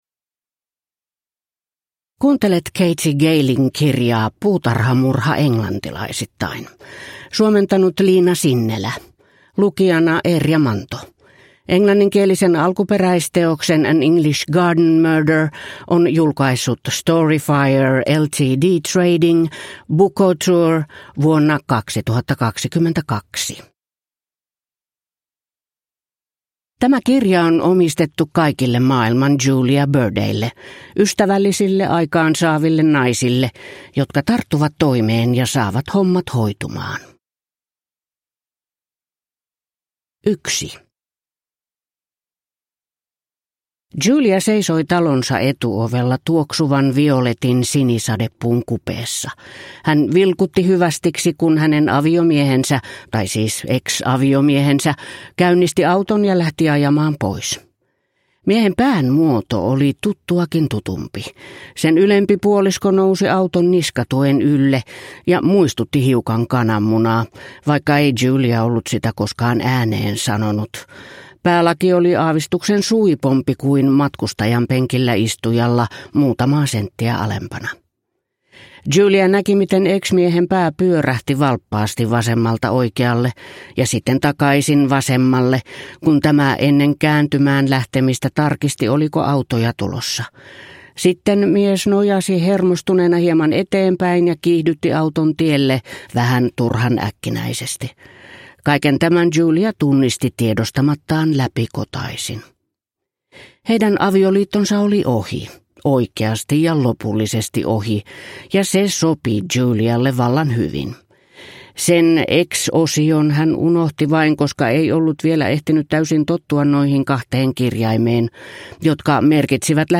Puutarhamurha englantilaisittain (ljudbok) av Katie Gayle